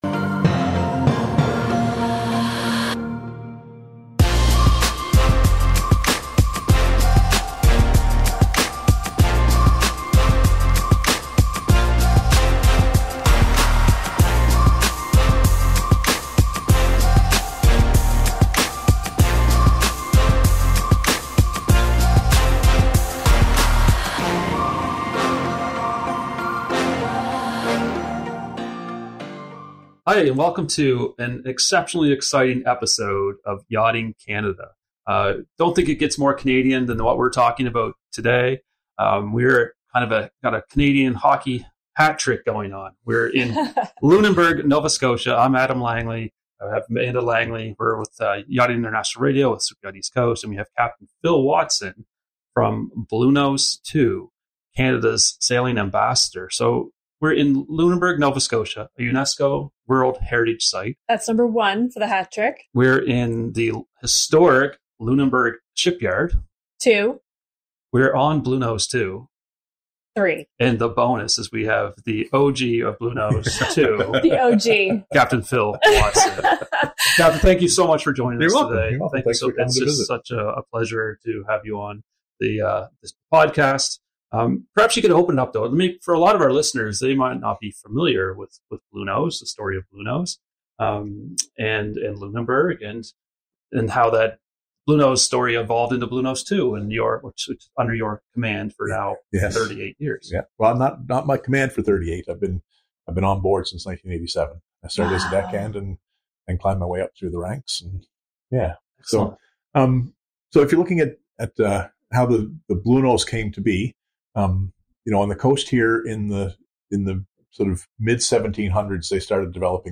Broadcasting from the historic Lunenburg shipyard, they explore the ship's origins, its role as Canada’s sailing ambassador, and its impact on Nova Scotia's maritime culture. Discover insights into the engineering of schooners, the Bluenose’s unbeaten racing history, and the vibrant community sustained by the shipyard.